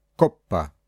1) c jest wymawiane jak /k/ oraz g jest wymiawiane jak /g/ przed a, o, u oraz przed spółgłoską: